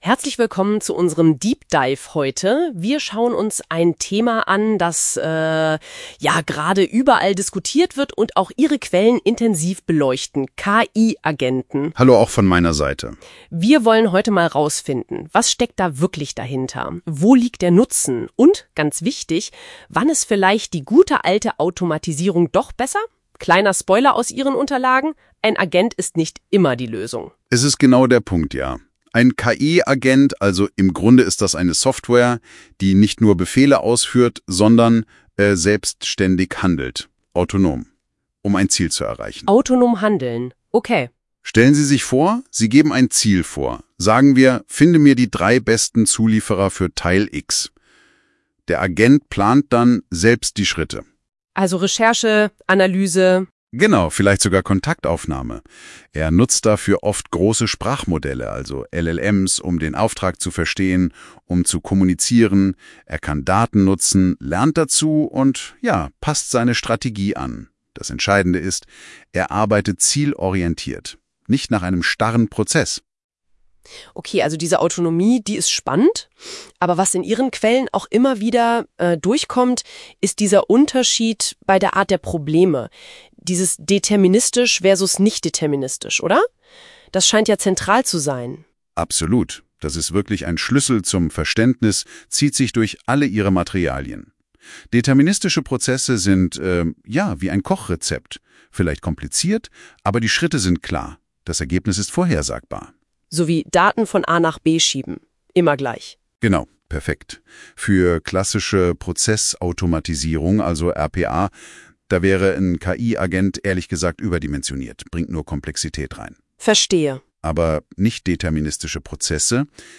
Deep-Dive-Podcast (mit KI erzeugt)